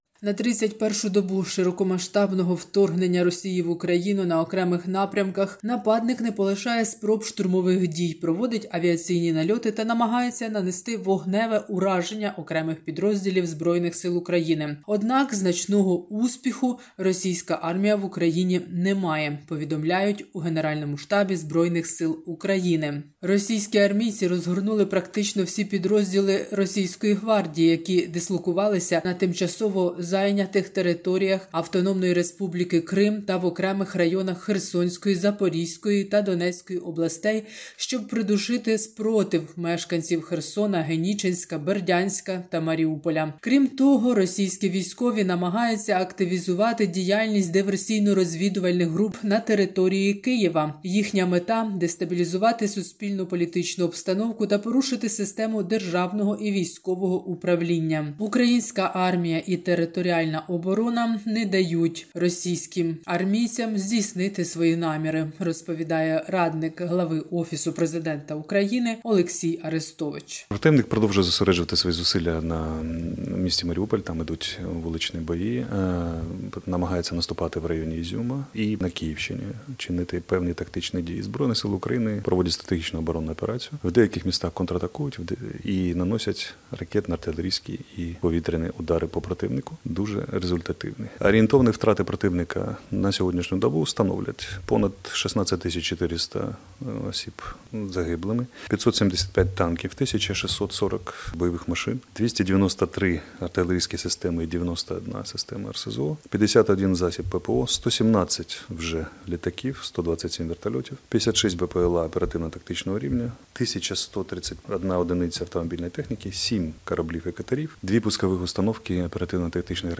Огляд новини з України - 27/03/2022